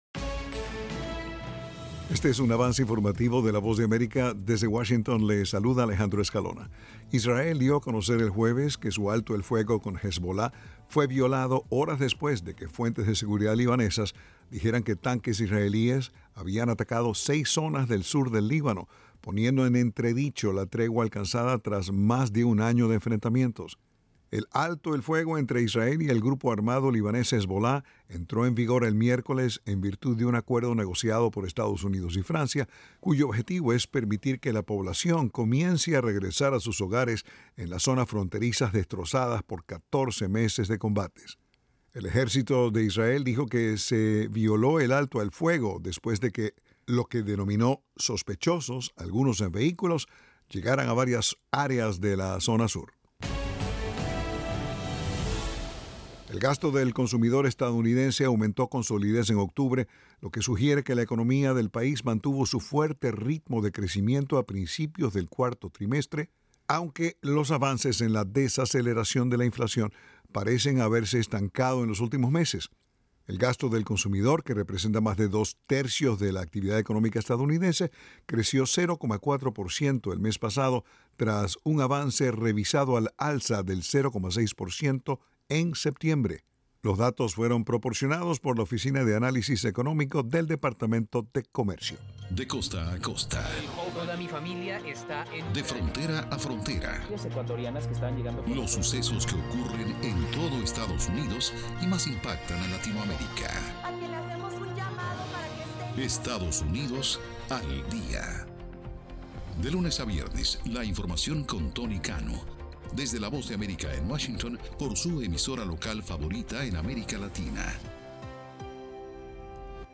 El siguiente es un avance informativo presentado por la Voz de América.